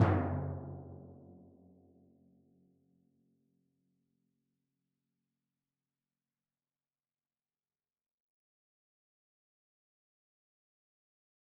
Timpani1_Hit_v4_rr1_Sum.mp3